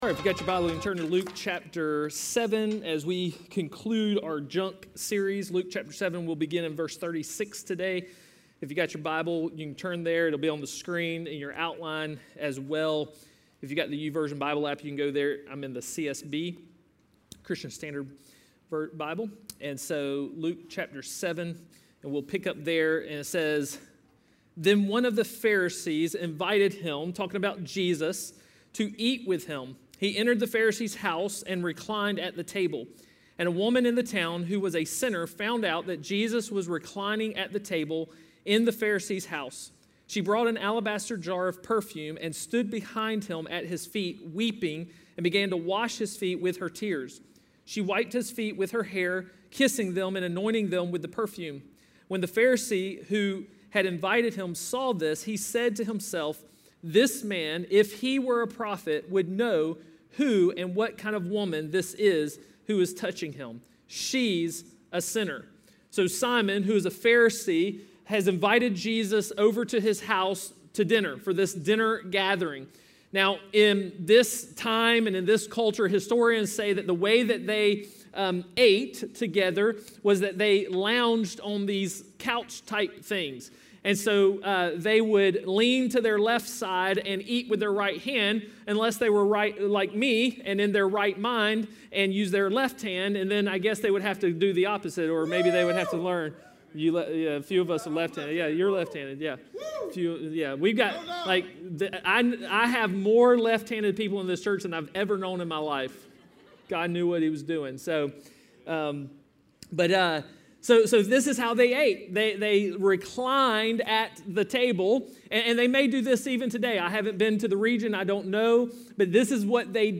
A message from the series "No Rival."